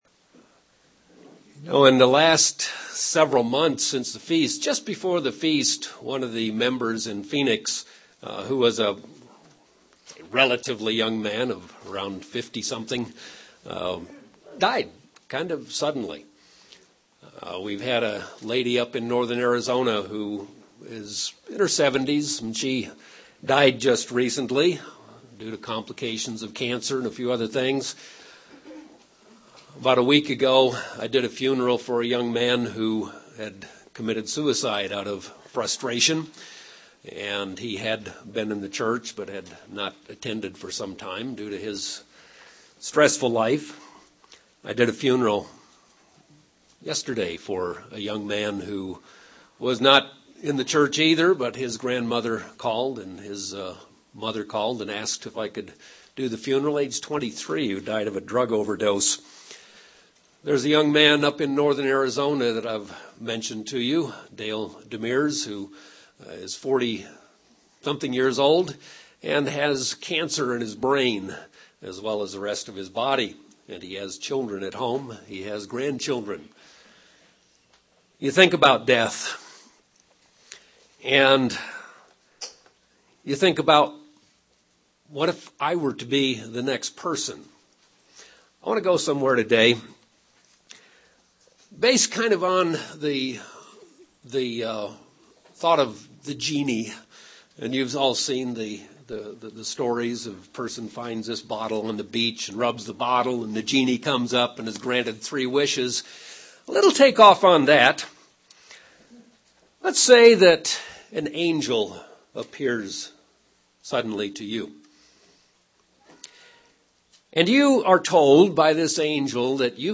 Given in Albuquerque, NM Phoenix East, AZ
UCG Sermon Studying the bible?